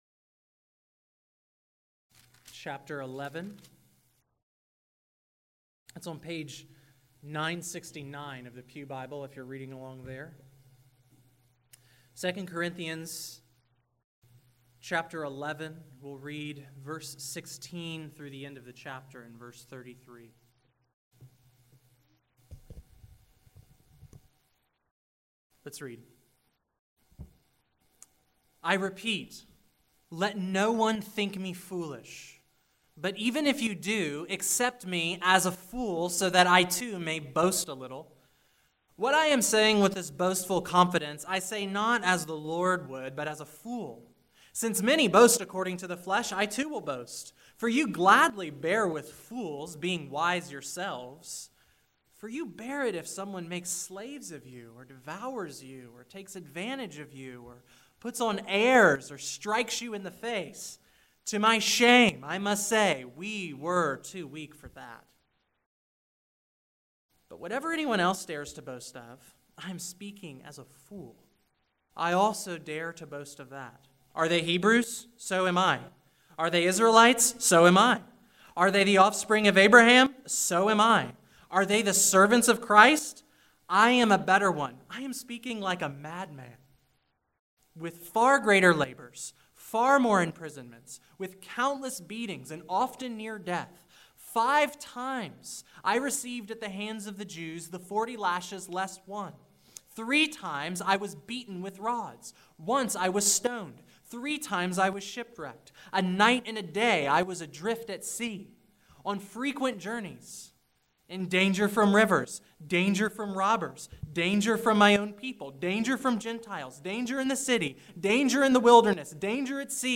The sermon continues the verse by verse series through 2 Corinthians.